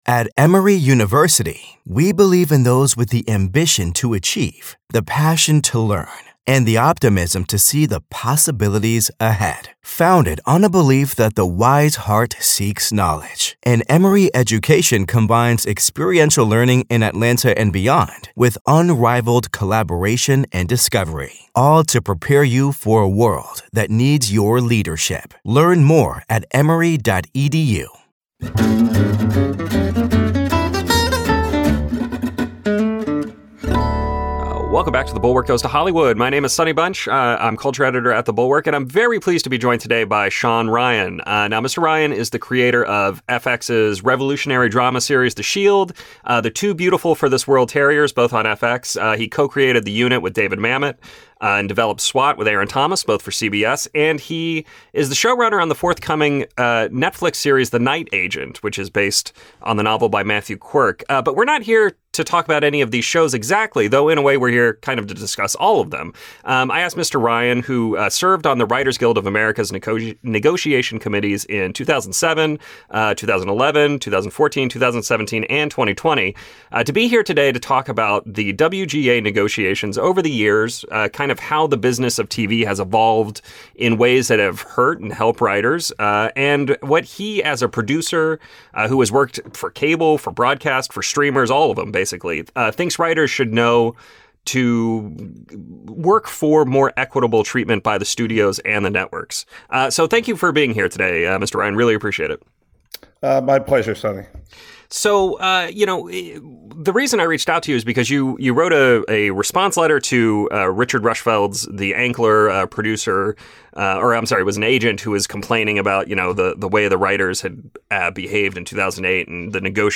interview: It’s not the writers who are saying there’s going to be a strike.